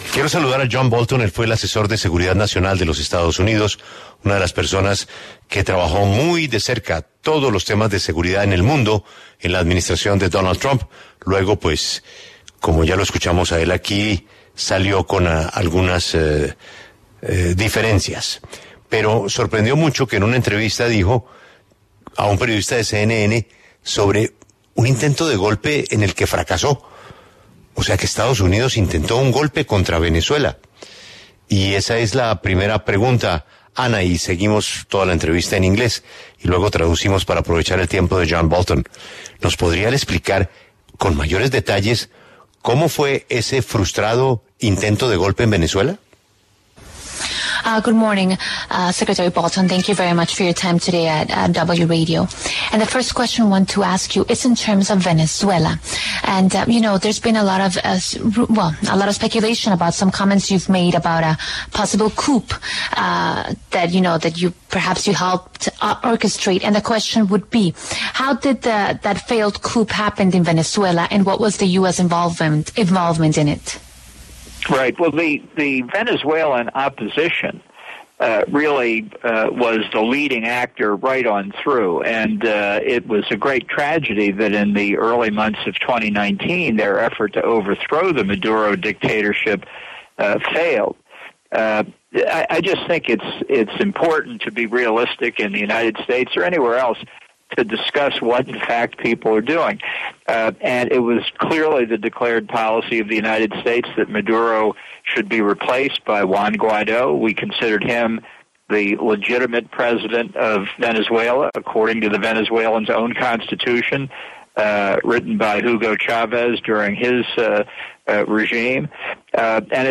John Bolton, exasesor de Seguridad Nacional de Estados Unidos, habló en exclusiva en La W, sobre lo que opina de las próximas elecciones del 2024 en su país.
A continuación, escuche la entrevista completa en La W con John Bolton, exasesor de Seguridad Nacional de Estados Unidos.